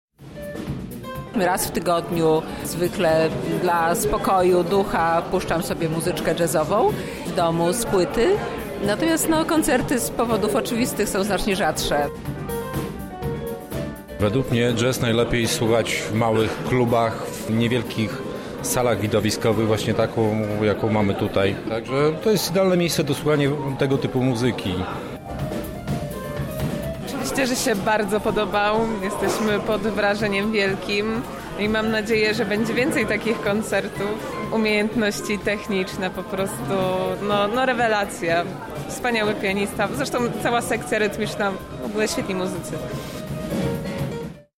brytyjski projekt jazzowy
kompozytor i pianista
basista
perkusista
przed lubelską publicznością w Centrum Kultury
koncert
Był to jeden z trzech koncertów w Polsce.